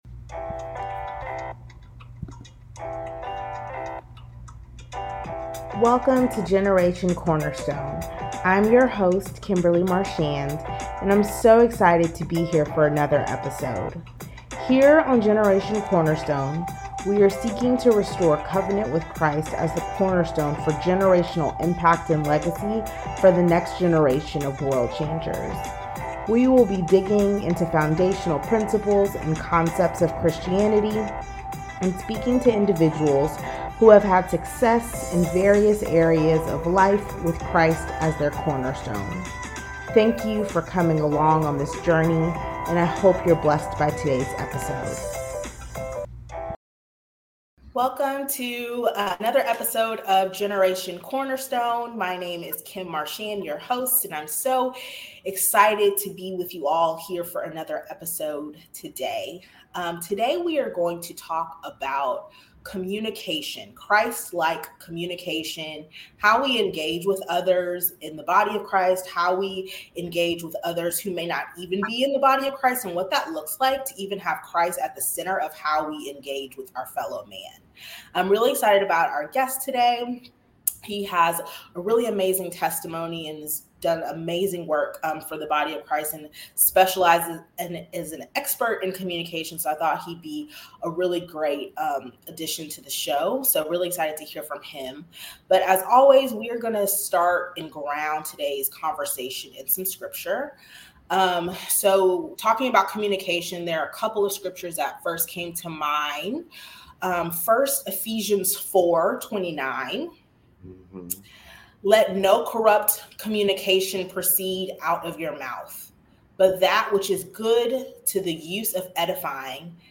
Join us for this enriching conversation on how we can learn to lovingly communicate with others.